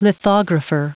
Prononciation du mot lithographer en anglais (fichier audio)
lithographer.wav